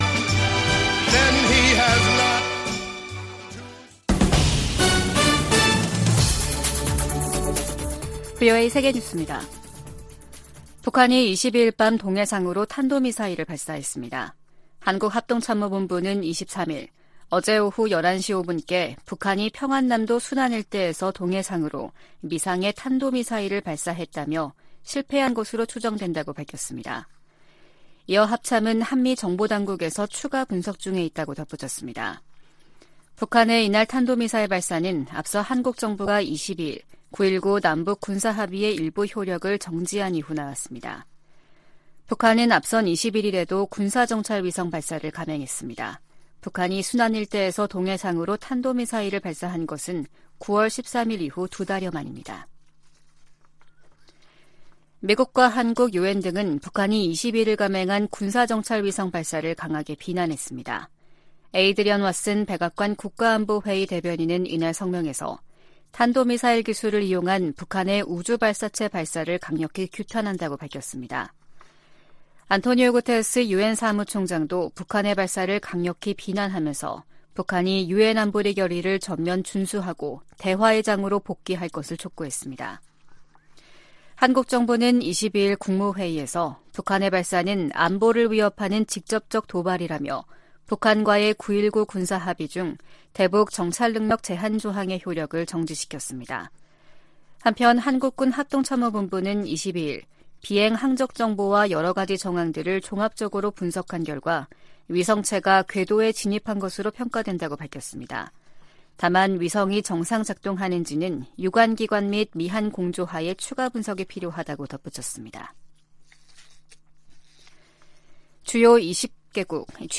VOA 한국어 아침 뉴스 프로그램 '워싱턴 뉴스 광장' 2023년 11월 23일 방송입니다. 북한이 군사정찰위성 발사 궤도 진입 성공을 발표하자 미국은 강하게 규탄하고 동맹 방어에 필요한 모든 조치를 취하겠다고 밝혔습니다. 한국 정부는 9.19 남북 군사합의 일부 효력을 정지시켰습니다. 유엔과 유럽연합(EU) 등도 북한의 3차 군사정찰위성 발사가 안보리 결의 위반이라며 강력하게 규탄했습니다.